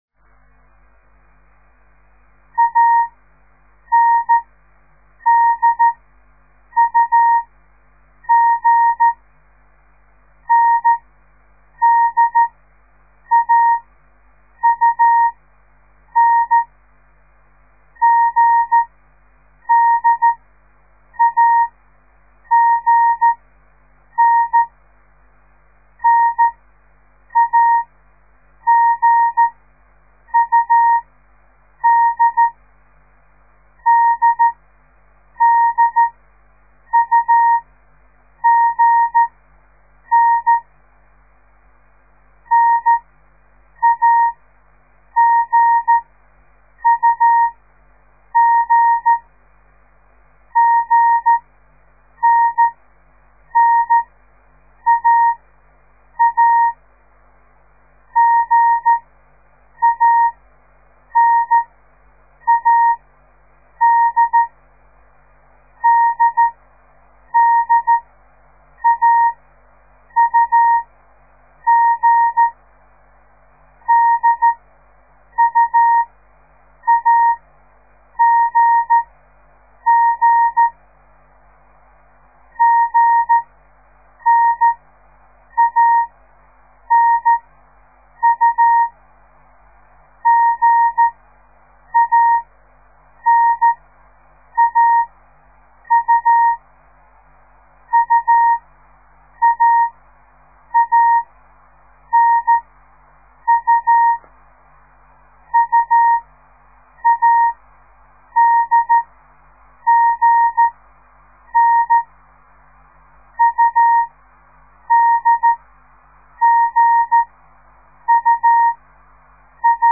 De geluidsfiles bestaan uit geseinde text in letter of cijfergroepen , steeds groepen van 5 tekens en iedere les bestaat uit 25 groepen
De letter A di-dah  /  de letter N dah-dit  / de letter D  dah-di-dit / de Letter U di-di-dah / de Letter G dah-dah-dit